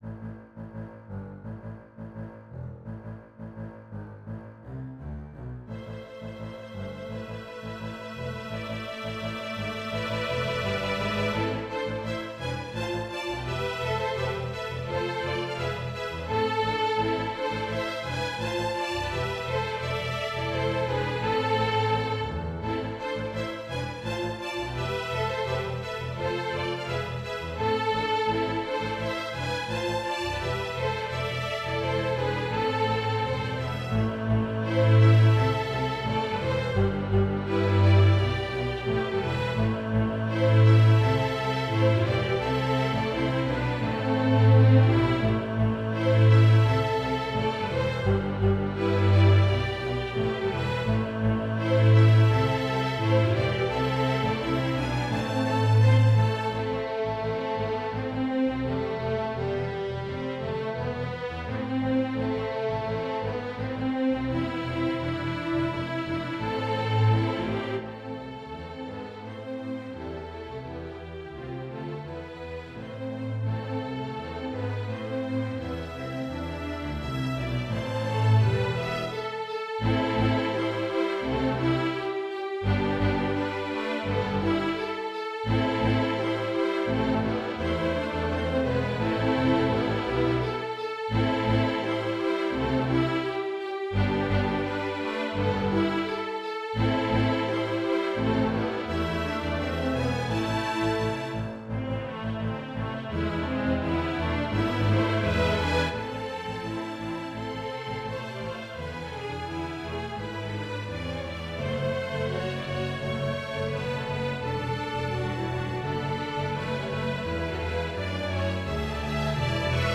Violin, Viola, Cello, Double Bass, String Orchestra